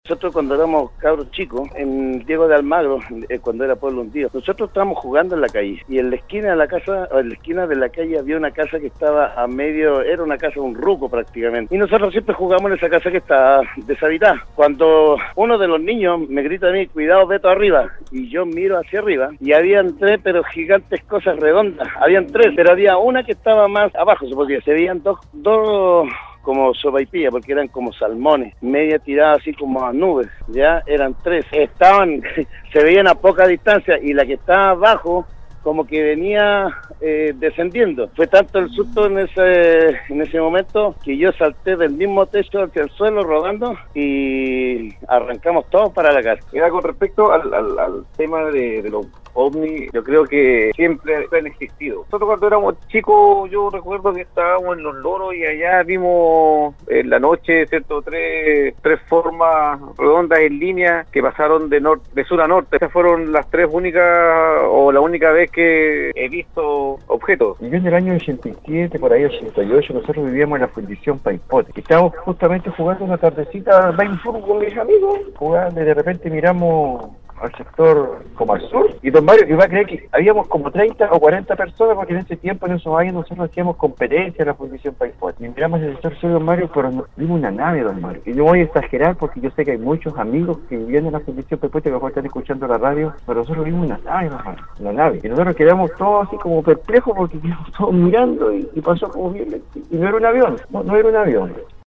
Foro en Nostálgica: Auditores contaron testimonios de avistamientos de ovnis en Atacama
Para la gran mayoría de los auditores que llamaron o enviaron mensajes de texto para opinar sobre el tema, los ovnis existen desde hace muchos años, incluso contaron algunas experiencias vivida en la región que los hacen pensar que no estamos solos en el mundo.